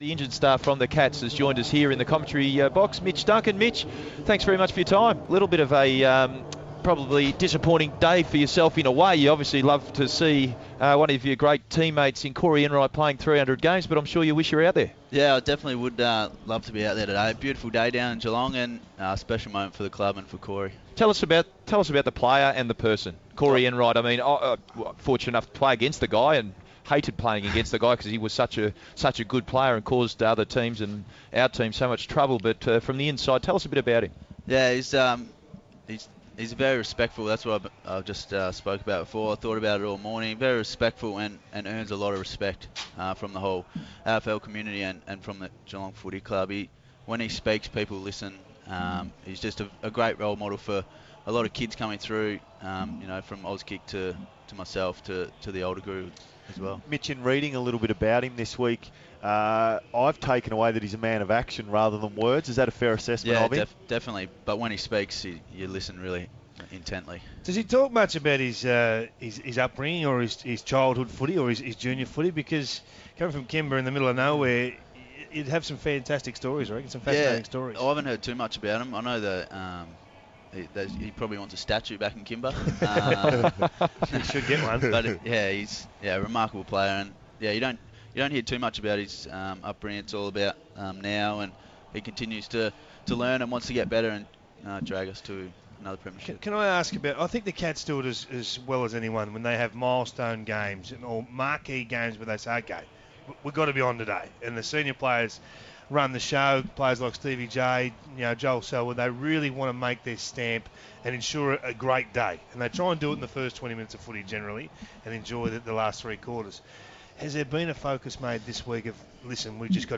Injured Geelong midfielder Mitch Duncan chats with Nathan Thompson, Scott Lucas and David King ahead of the Cats taking on Melbourne.